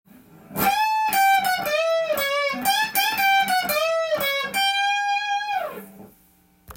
Shotgun Bluesで弾いているフレーズを耳コピしてみました。
譜面通り弾いてみました
Emペンタトニックスケールが主になります。
音符は連打する時はダウンピッキング。
エレキギターでカンタンに弾けるブルース